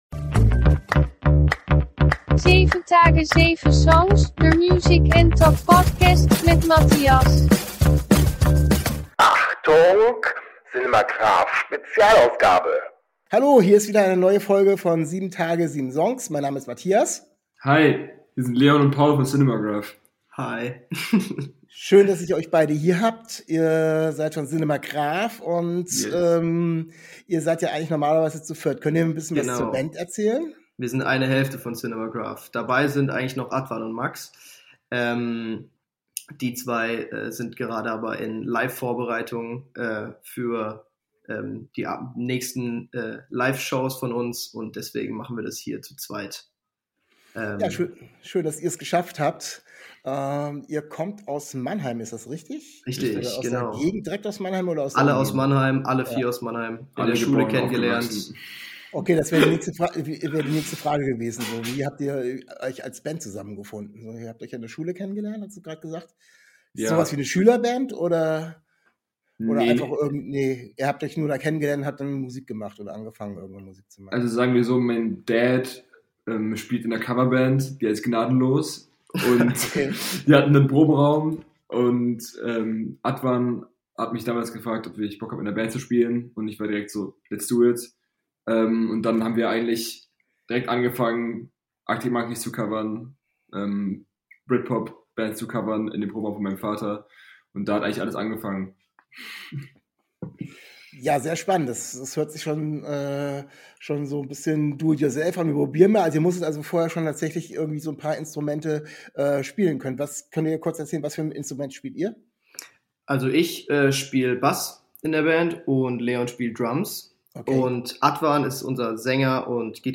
In dieser Folge sind Musiker der Mannheimer Indie- Rock Band CINEMAGRAPH zu Gast. Sie stellen ihre neue EP vor und erzählen von ihrer Bandgeschichte.